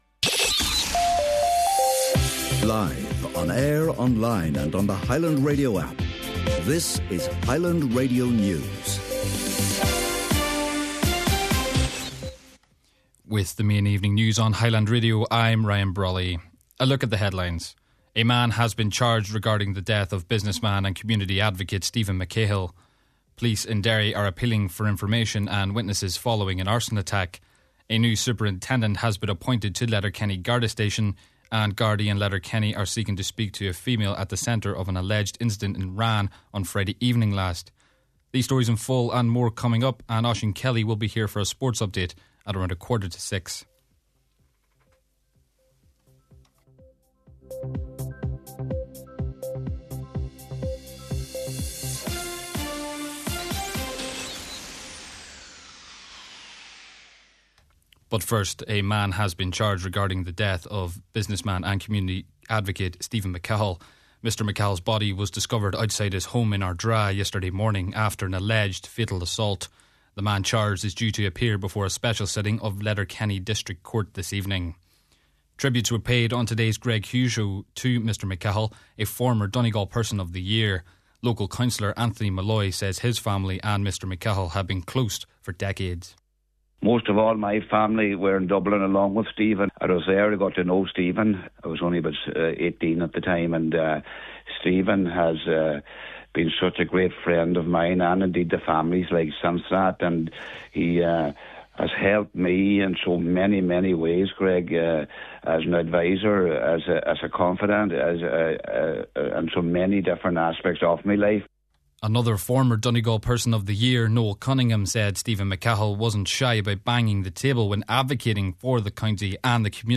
Main Evening News, Sport & Obituary Notices – Tuesday January 6th